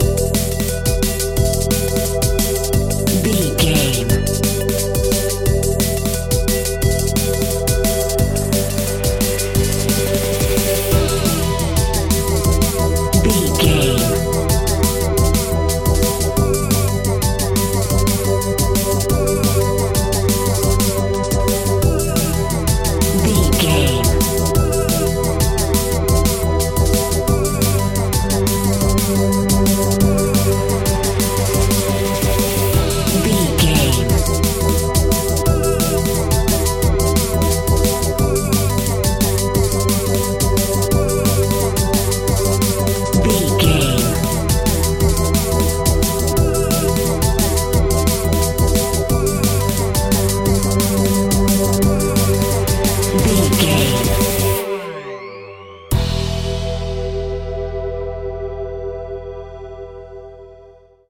Aeolian/Minor
Fast
aggressive
powerful
uplifting
futuristic
hypnotic
industrial
dreamy
drum machine
synthesiser
electronic
sub bass
synth leads
synth bass